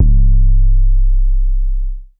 Immortal_808.wav